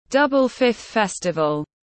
Tết Đoan Ngọ tiếng anh gọi là Double Fifth Festival, phiên âm tiếng anh đọc là /ˈdʌbl fɪfθ ˈfɛstəvəl/